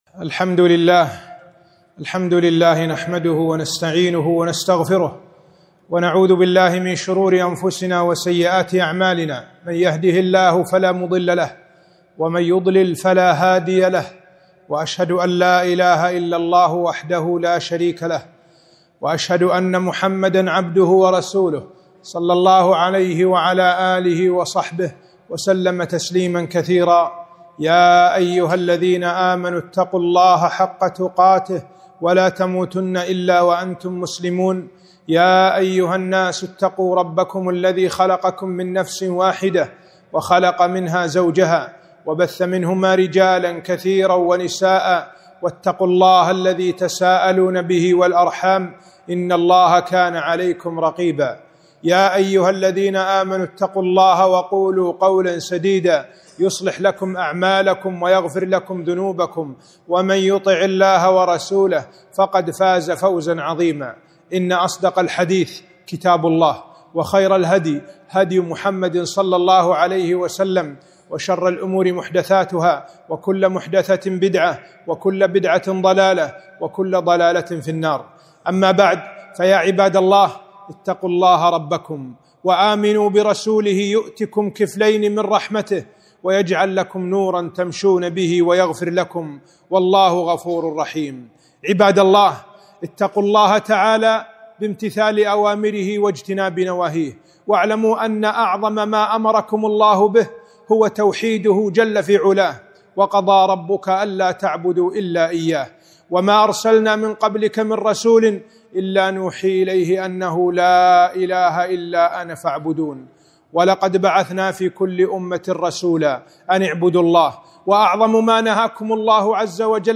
خطبة - ( وما خلقت الجن والأنس إلا ليعبدون )